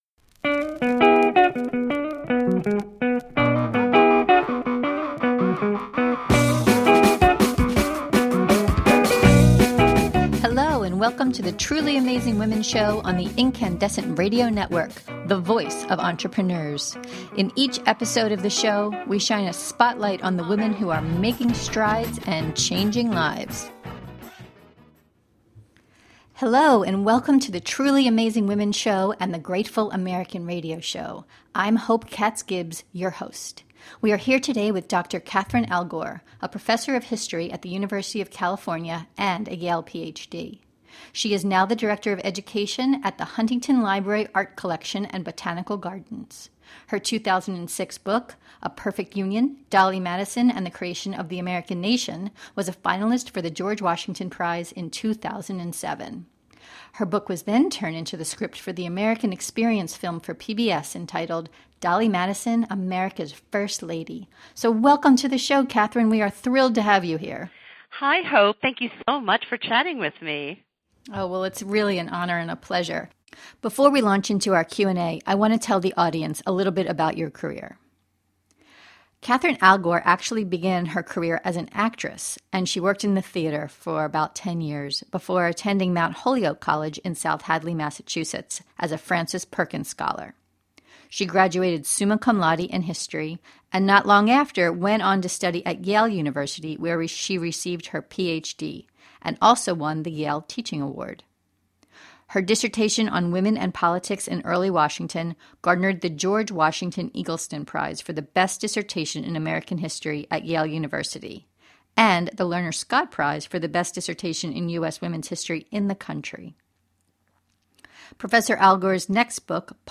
In this podcast interview you’ll learn: While we certainly have all known about her since we studied history in elementary school — we don’t really know about the impact that Dolley made. Three things that we should all know about this truly amazing woman.